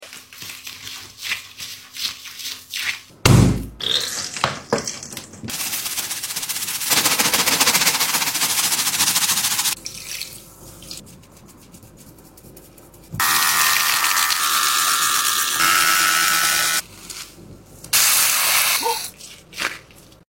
CLEANING SQUISHY ASMR🐾🐾Cat Paw#asmr